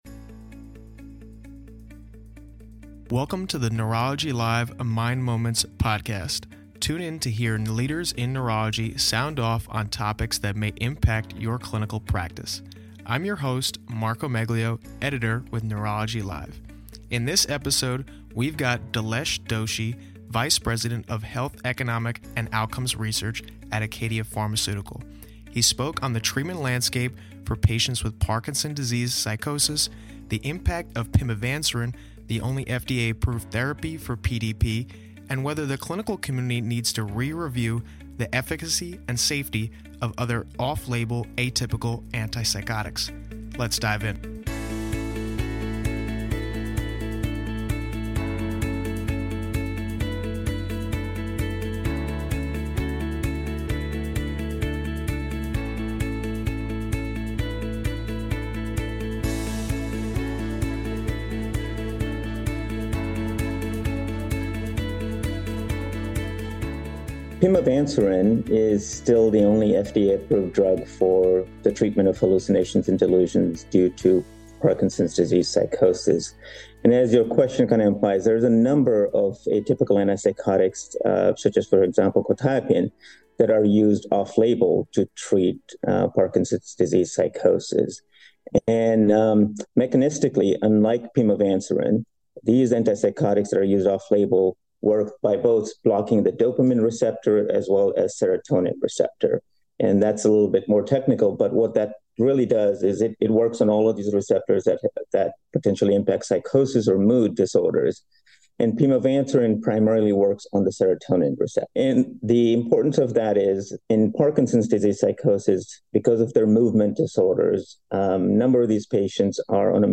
Tune in to hear leaders in neurology sound off on topics that impact your clinical practice.